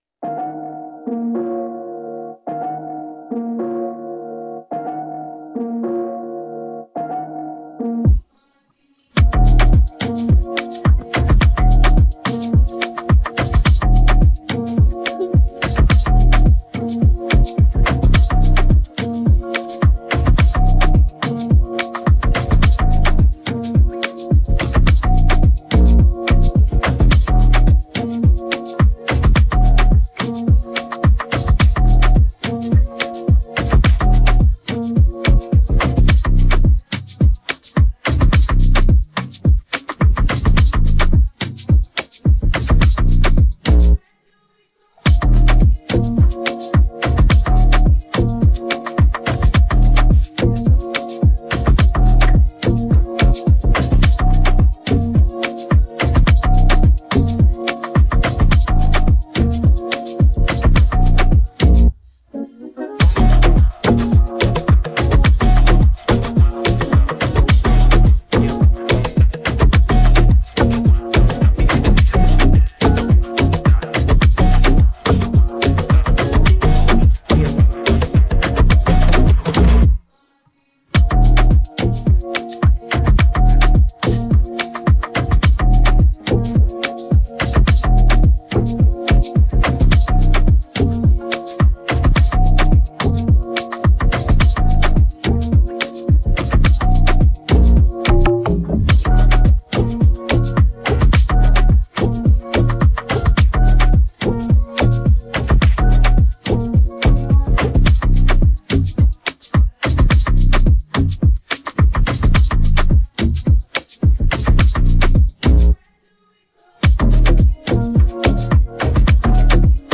Afro popAfrobeats
fusion of Afrobeats and pop elements